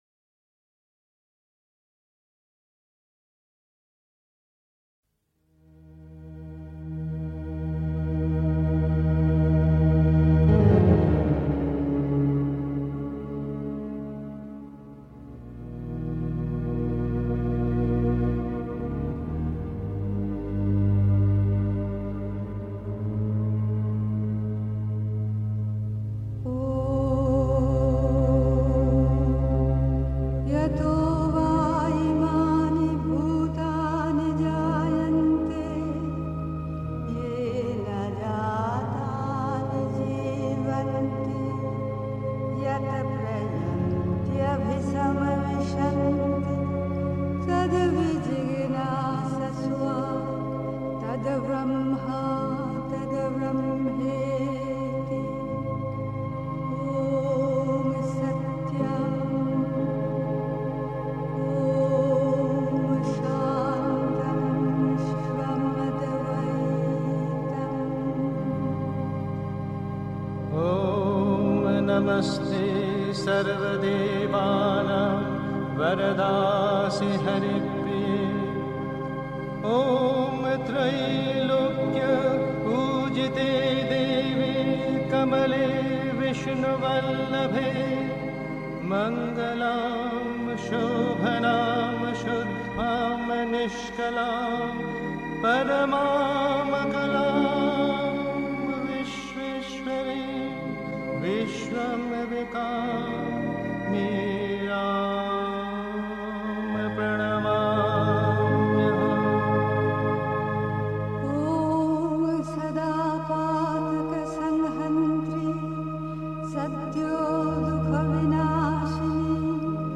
Pondicherry. 2. Selbstbeherrschung aufgeben heißt, dem Vital freies Spiel lassen (Sri Aurobindo, CWSA Vol 29, p. 119) 3. Zwölf Minuten Stille.